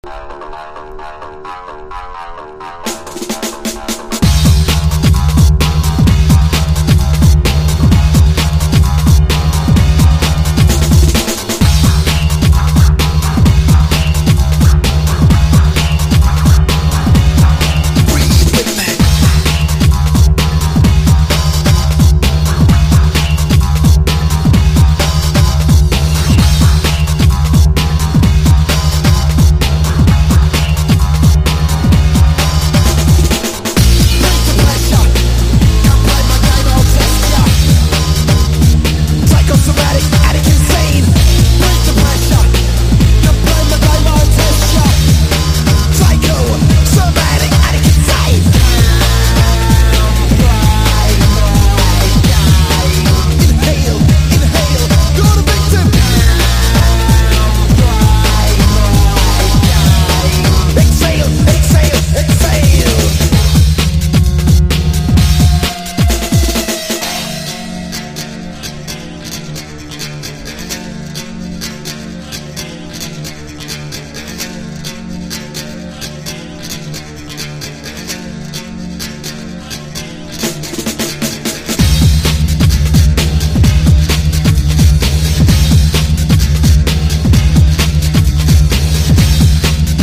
激しいビートがフロアを揺らす特大バンギン・ナンバー！
BREAK BEATS / BIG BEAT